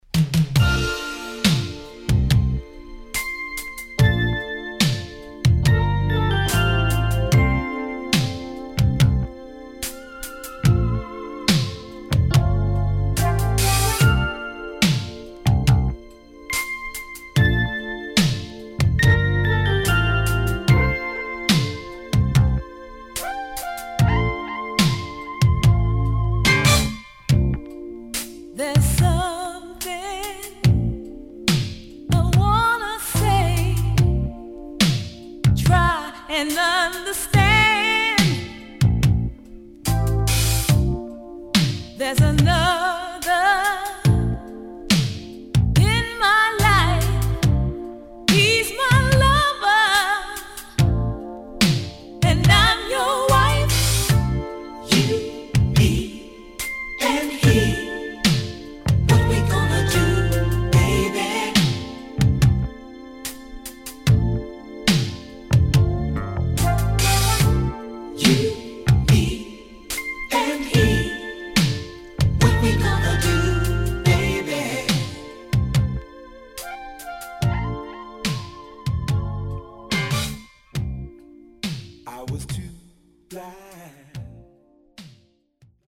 Heavyなビートの定番サンプリングネタ。